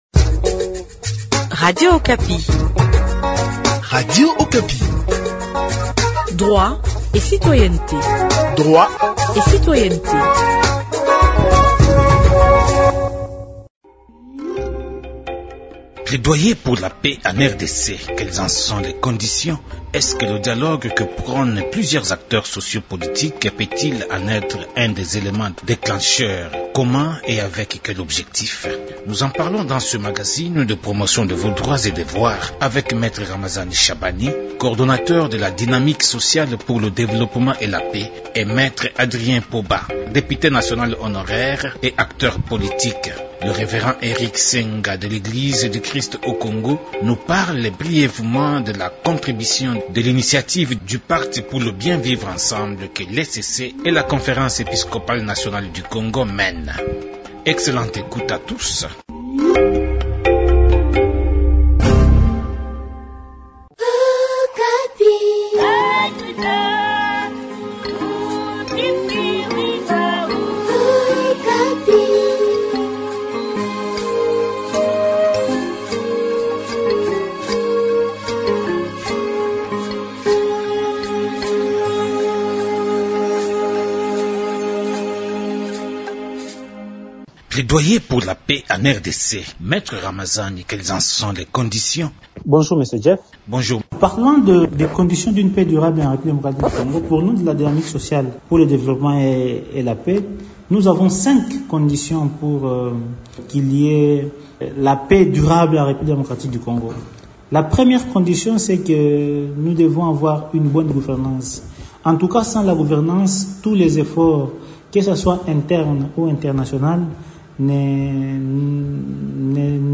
Plaidoyer pour la paix en Rdc : deux avocats se contredisent sur les conditions d’une paix durable en Rd Congo.